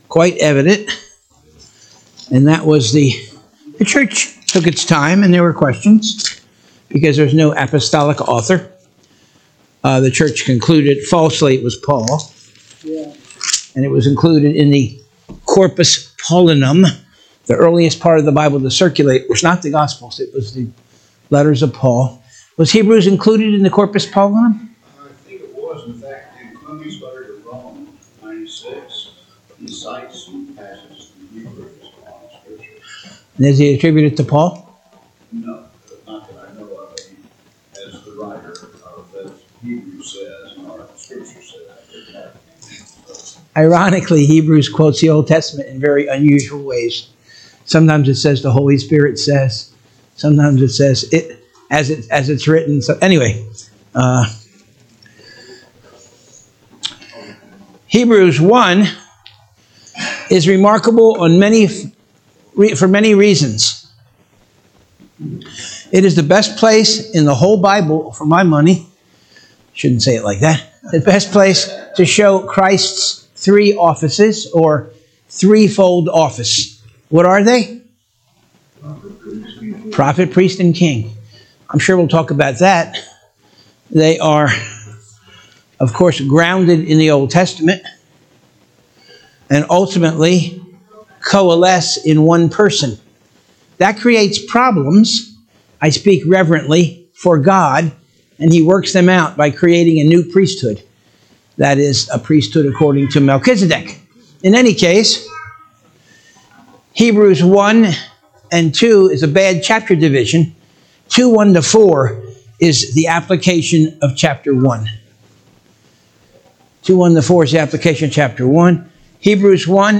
Sunday School 4-6-2025 - Covenant of Grace Church